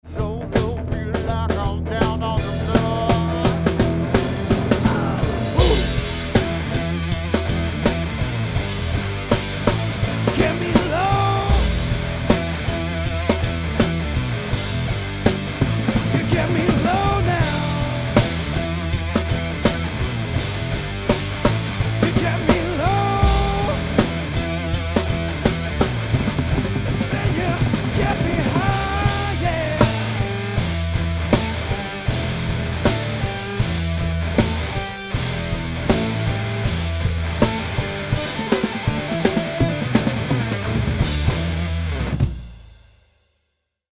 Caution: Loud
Mixed & Mastered
Mixed (No Mastering)
Before / Raw Tracks